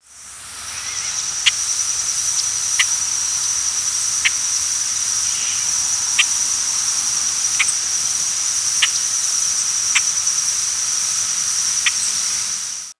Boat-tailed Grackle diurnal flight calls
Bird in flight with Yellow-rumped Warbler and Gray Catbird calling in the background.